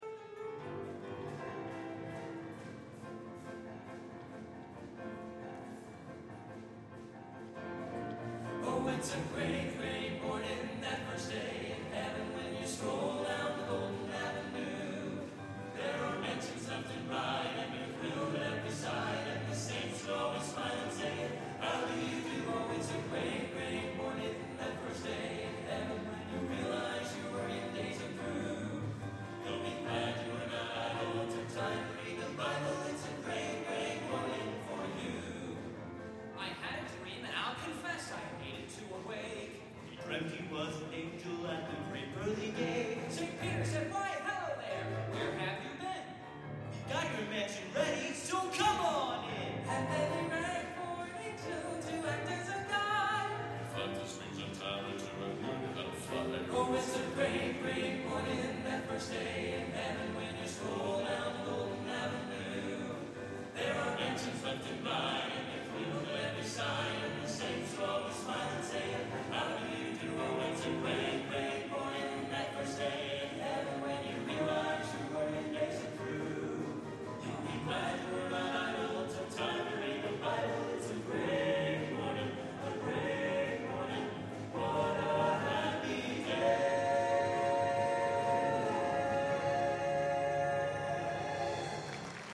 Location: Bonita Springs, Florida